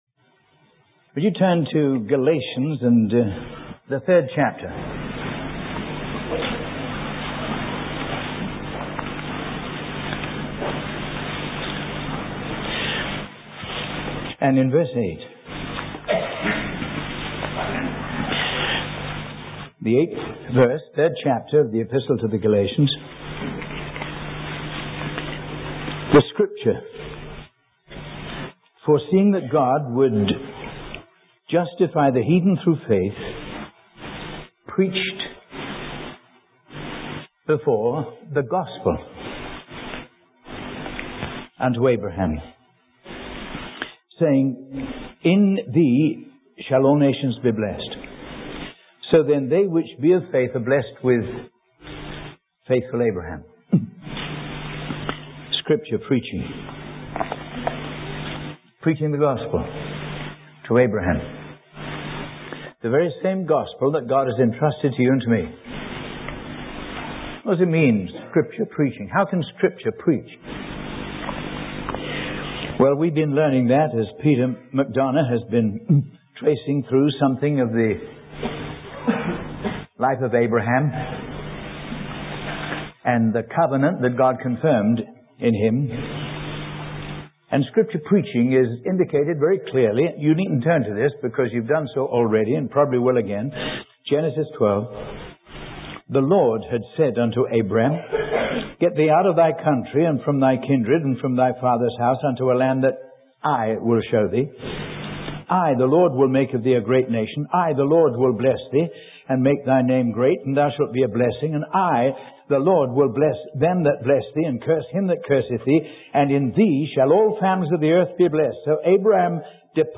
He explains that God's covenant with Abraham foreshadowed the coming of Christ, who fulfilled the law's requirements through His sacrificial death and resurrection. The sermon illustrates that salvation is not about adhering to the law but about receiving the life of Christ through faith, which brings about regeneration and a new relationship with God.